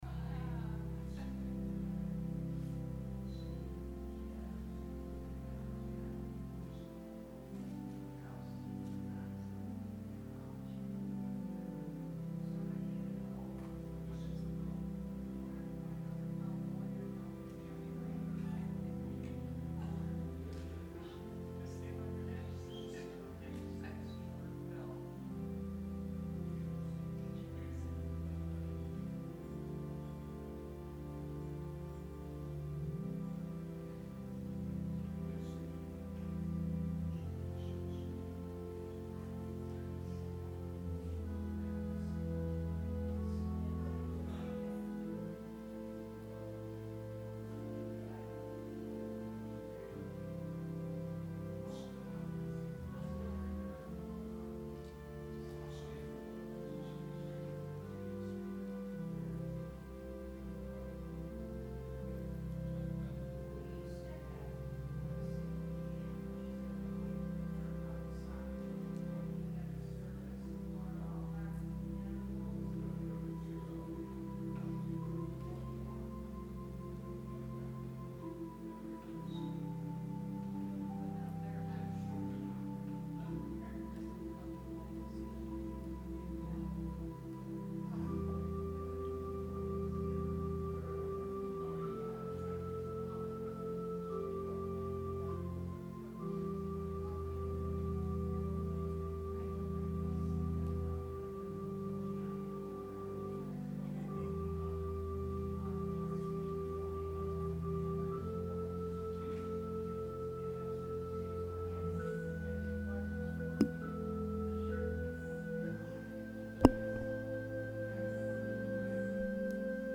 Sermon – September 22, 2019